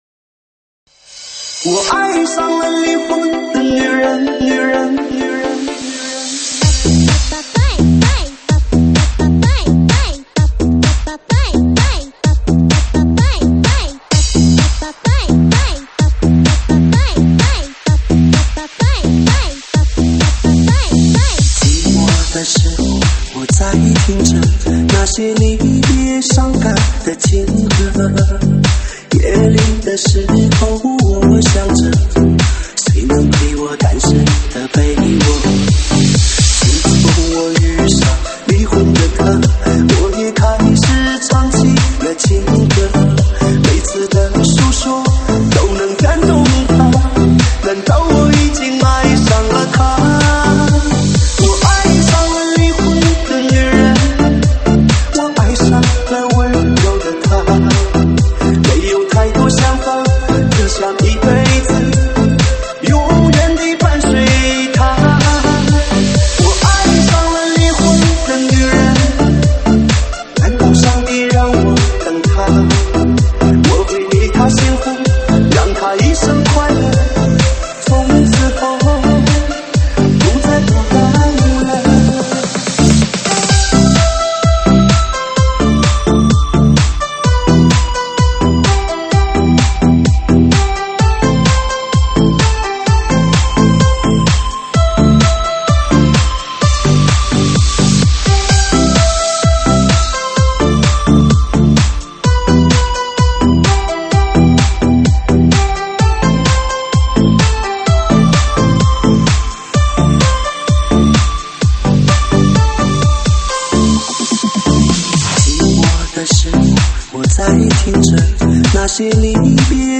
中文舞曲
舞曲类别：中文舞曲